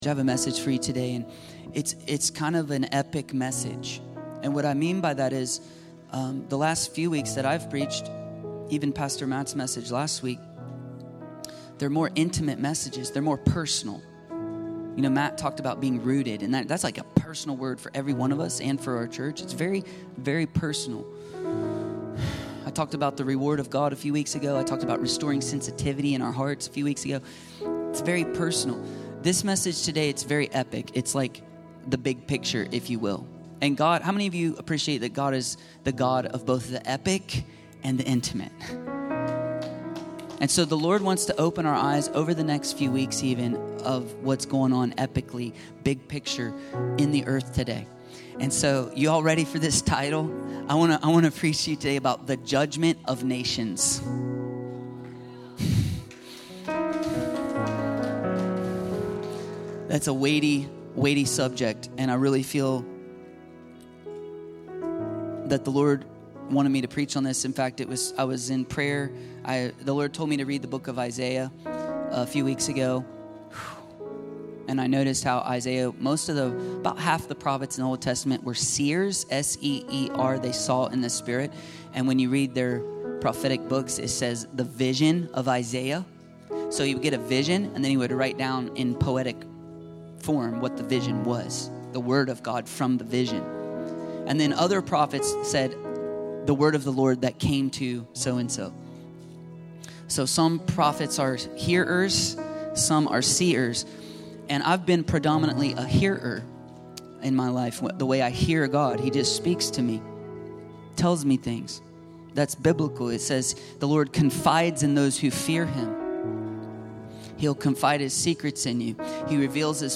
The Judgement of Nations - The Judgement of Nations ~ Free People Church: AUDIO Sermons Podcast